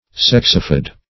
sexifid - definition of sexifid - synonyms, pronunciation, spelling from Free Dictionary
Search Result for " sexifid" : The Collaborative International Dictionary of English v.0.48: Sexfid \Sex"fid\, Sexifid \Sex"i*fid\, a. [Sex- + root of L. findere to split: cf. F. sexfide.]